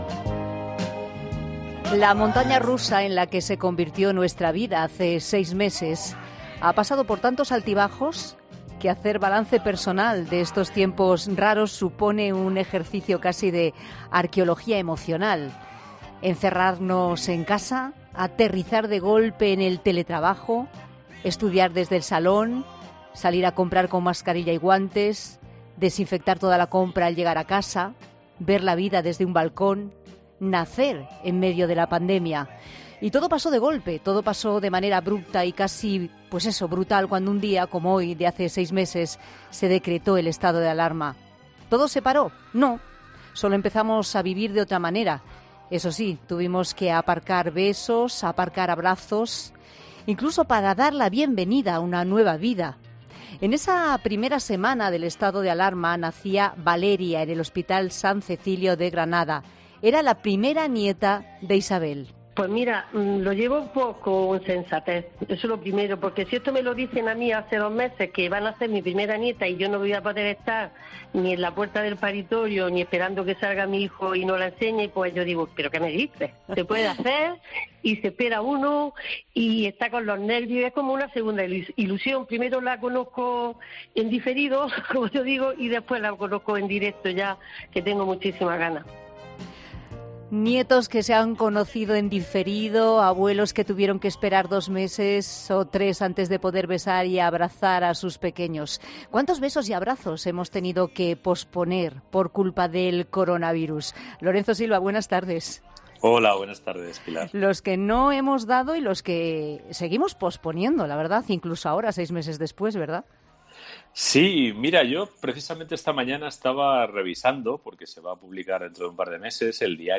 El colaborador de 'La Tarde' ha reflexionado sobre todos aquellos abrazos que no hemos podido dar desde que hace seis meses se decretara el estado de alarma en España